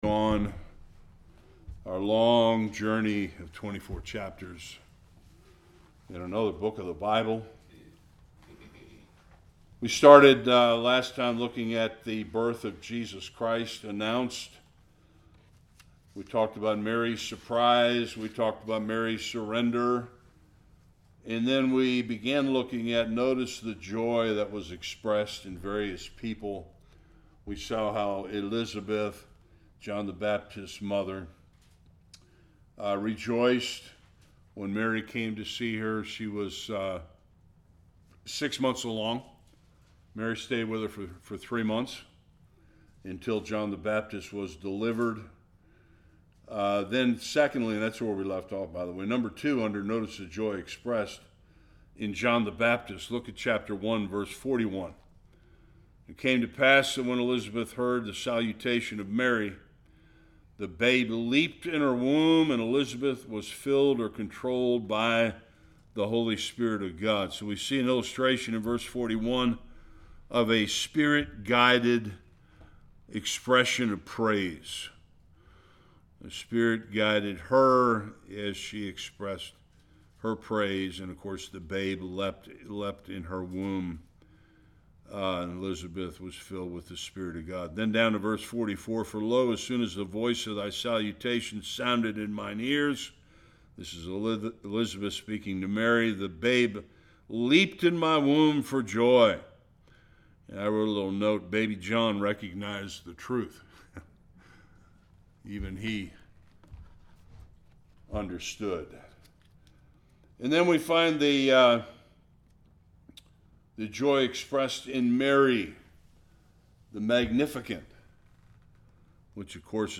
46-80 Service Type: Bible Study The beautiful songs of Mary